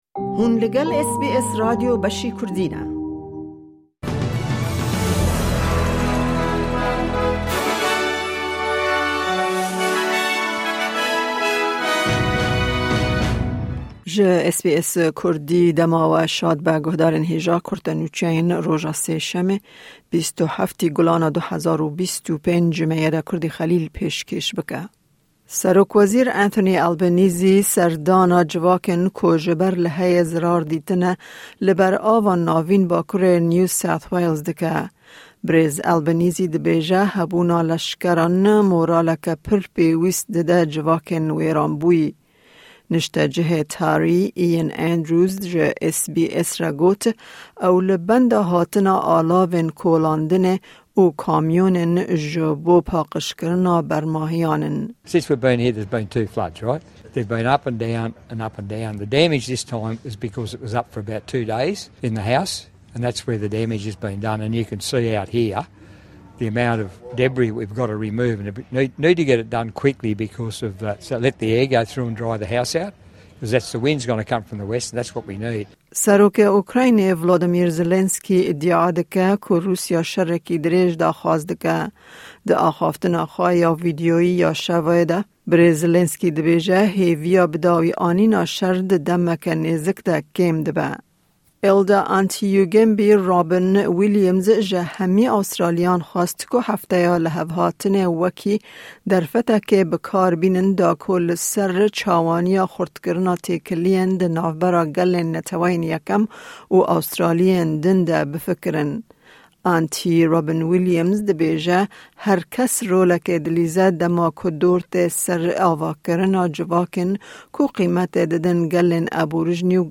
Kurte Nûçeyên roja Sêşemê 27î Gulana 2025